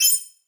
s006_MiniGameGetHeart.wav